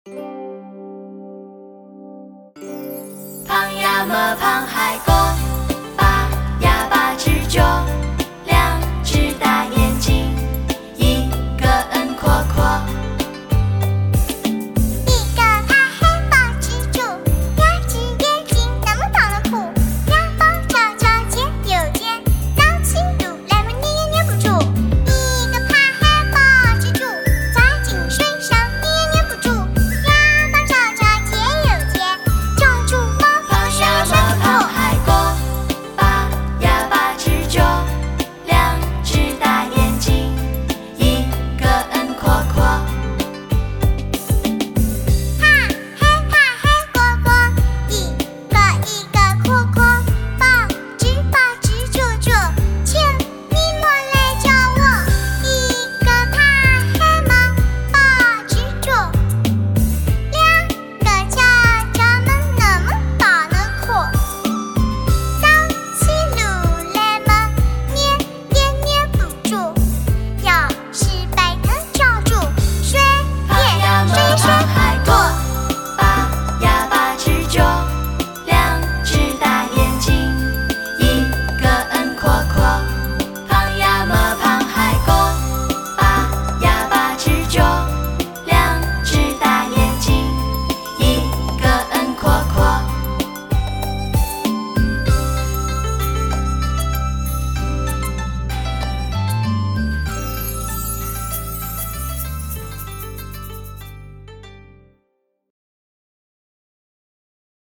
[19/4/2009][儿歌]螃蟹歌 激动社区，陪你一起慢慢变老！